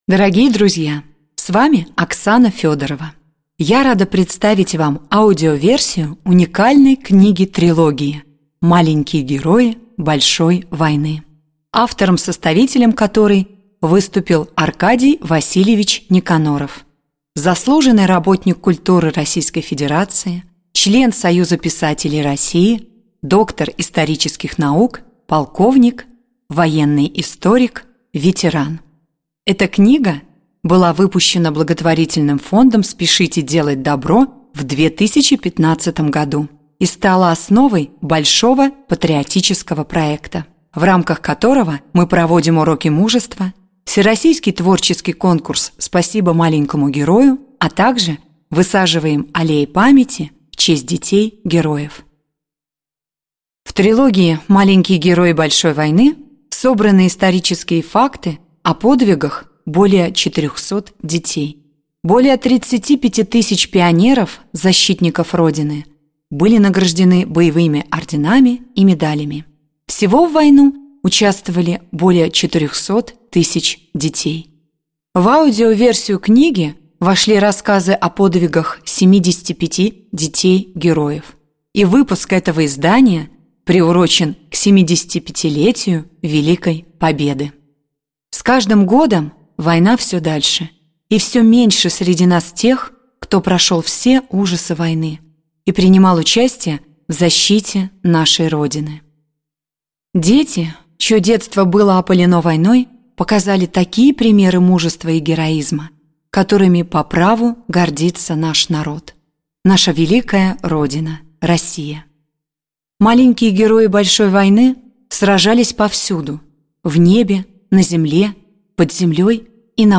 Аудиокнига Маленькие герои большой войны | Библиотека аудиокниг
Aудиокнига Маленькие герои большой войны Автор Группа авторов Читает аудиокнигу Актерский коллектив.